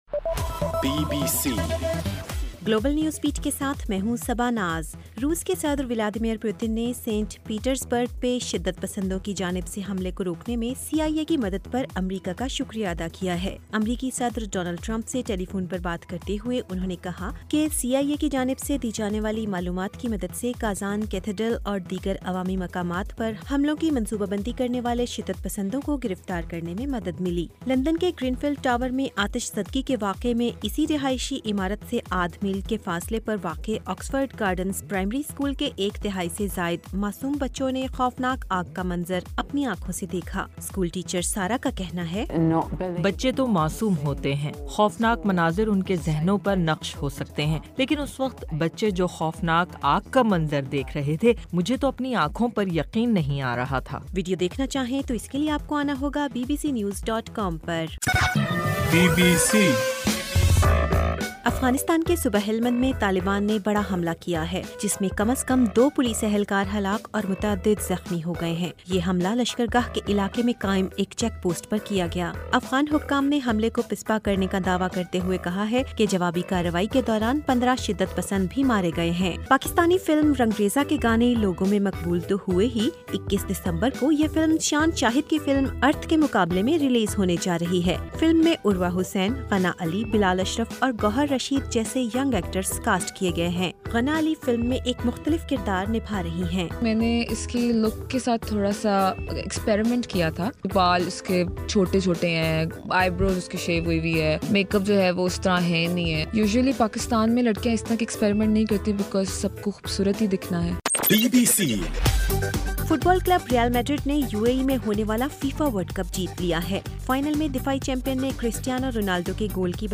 گلوبل نیوز بیٹ بُلیٹن اُردو زبان میں رات 8 بجے سے صبح 1 بجے تک ہر گھنٹےکے بعد اپنا اور آواز ایفایم ریڈیو سٹیشن کے علاوہ ٹوئٹر، فیس بُک اور آڈیو بوم پر ضرور سنیے